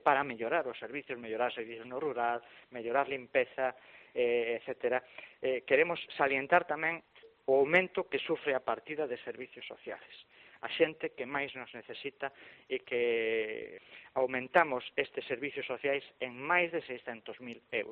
AUDIO: El concejal de Facenda de Boiro, Luis Ruiz, en declaraciones a Herrera en COPE de las Rías